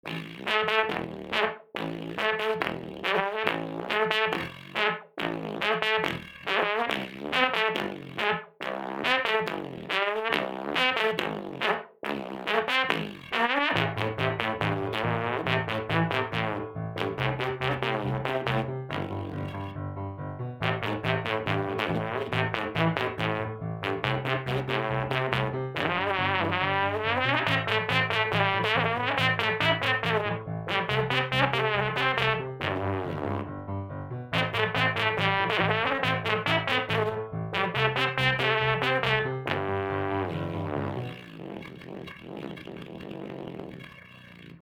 Вот, кстати, фуга или миньет.